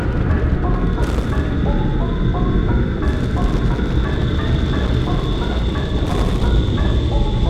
In DSP LLE Recompiler, this doesn't happen and the audio plays properly.